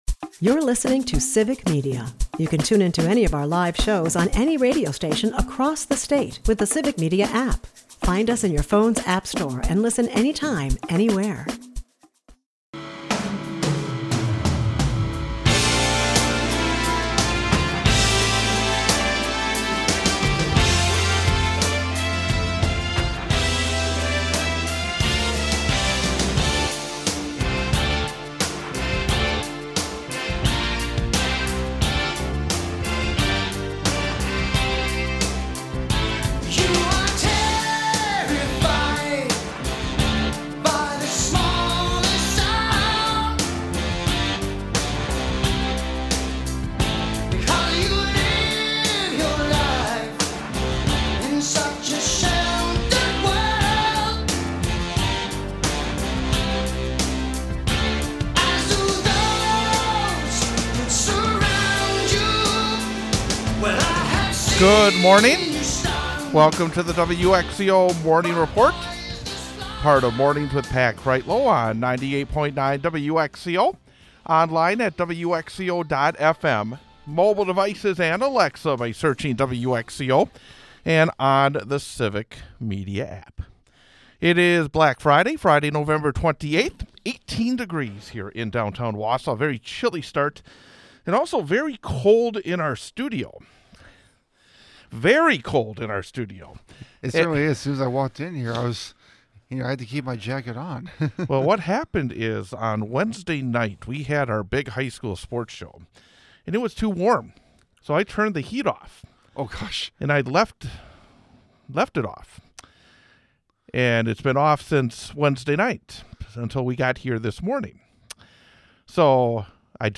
High school basketball takes center stage with a doubleheader featuring Newman Catholic and Wausau West. Meanwhile, winter storm warnings loom, threatening to blanket the region with heavy snow. On a lighter note, the hosts dive into a whimsical debate about Bigfoot's existence, with a surprising 28% of Americans believing in the creature.